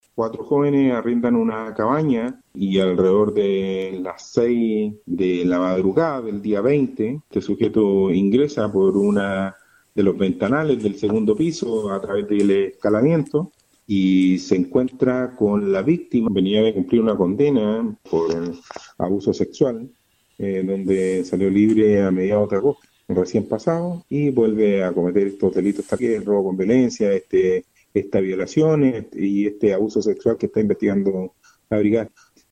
El oficial de la PDI confirmó que a mediados del mes de agosto este sujeto había salido de la cárcel, concretando lamentablemente la semana pasada el delito por el cual fue detenido nuevamente.